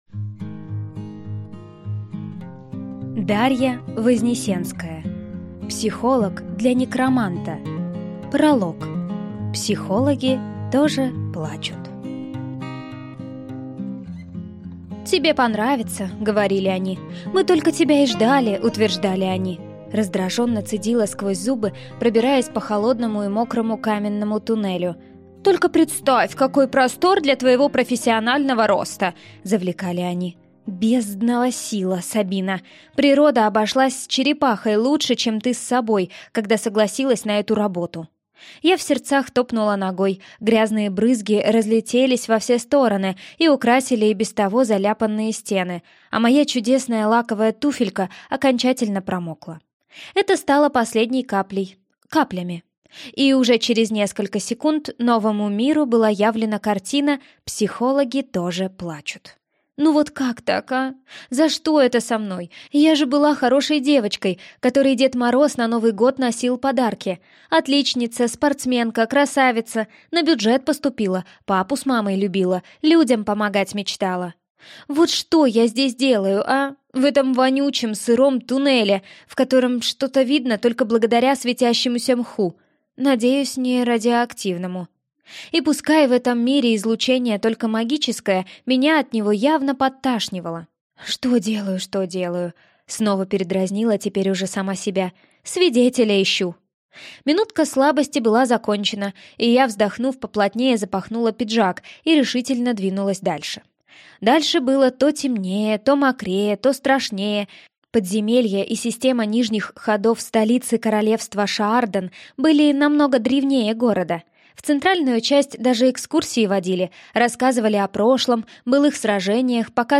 Аудиокнига Психолог для некроманта | Библиотека аудиокниг